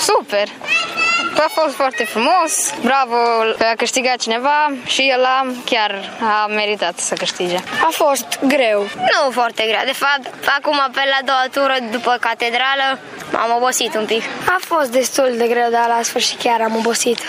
Pentru unii dintre concurenţi efortul a fost destul de mare, mai ales pentru cei neobişnuiţi cu sportul: